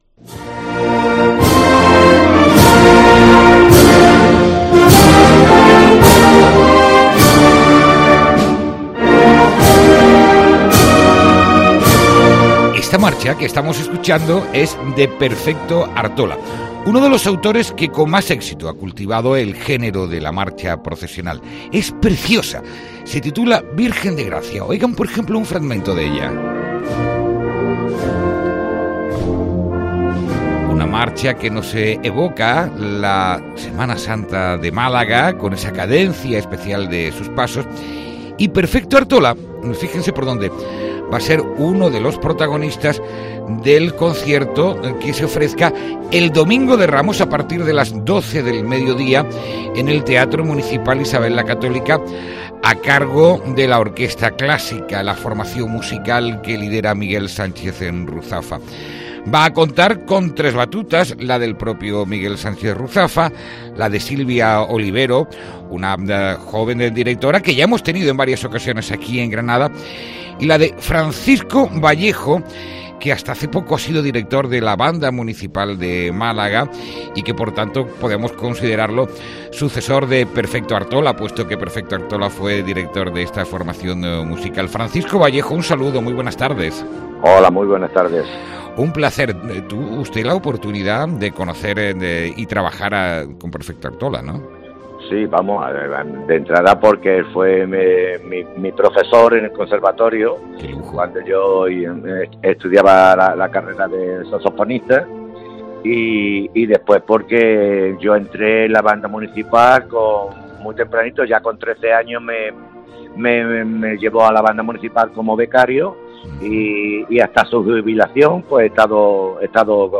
ENTREVISTA|| Concierto de Semana Santa de la Orquesta Clásica de Granada